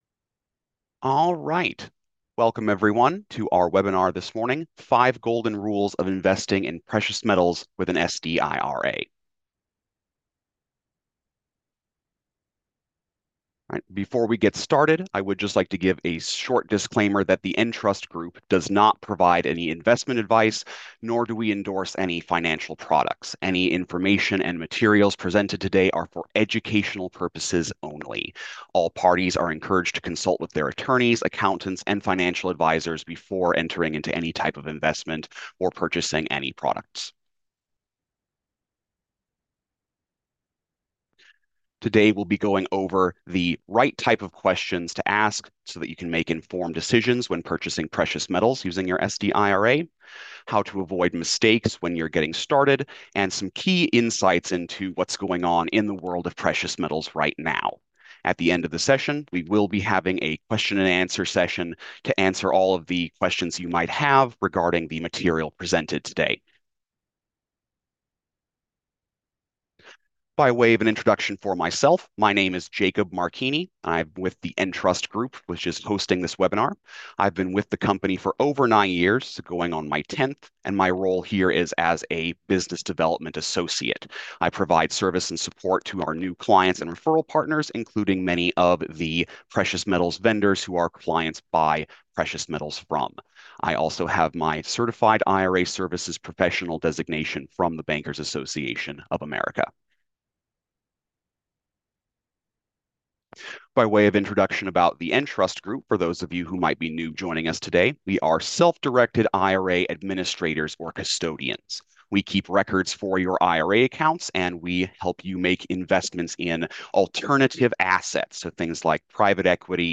Webinar_May_2025_Audio_Replay.m4a